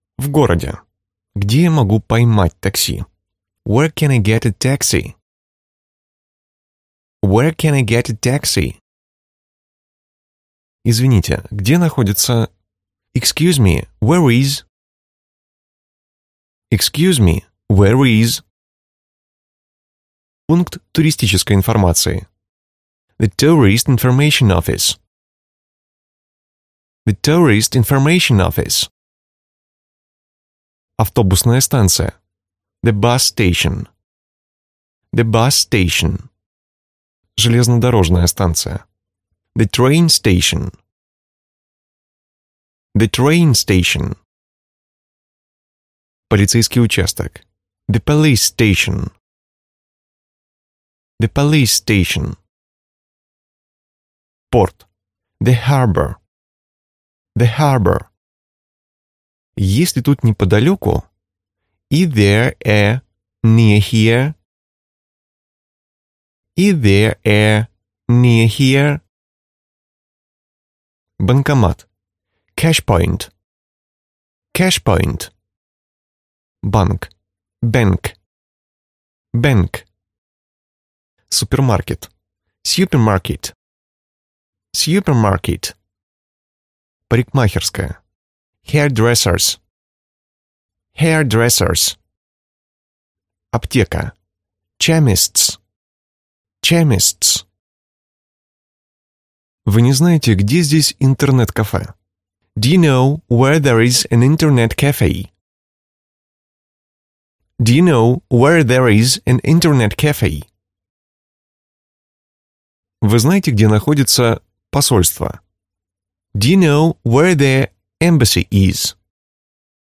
Аудиокнига Английский язык. Полный курс | Библиотека аудиокниг